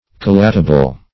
Search Result for " collatable" : The Collaborative International Dictionary of English v.0.48: Collatable \Col*lat"a*ble\ (k[o^]l*l[=a]t"[.a]*b'l), a. Capable of being collated.
collatable.mp3